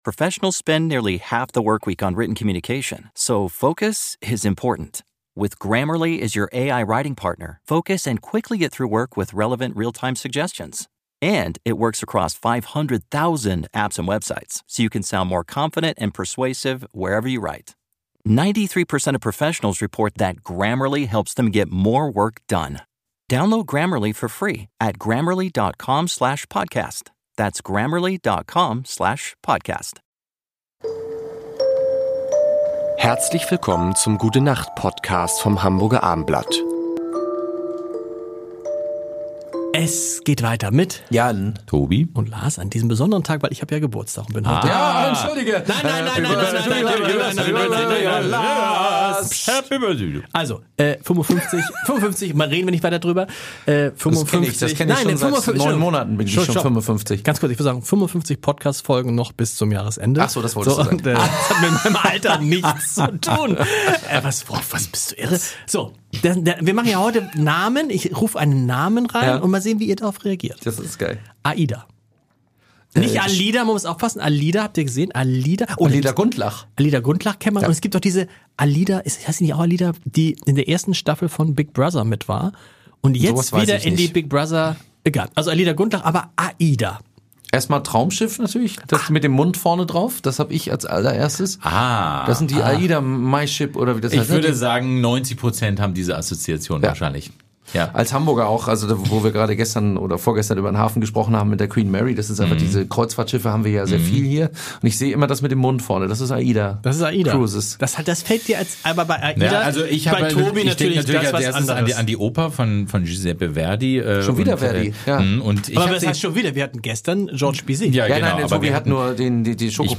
Mit unterhaltsamen Geschichten, beruhigenden Klängen und interessanten Gesprächen helfen wir dir dabei, abzuschalten und besser einzuschlafen.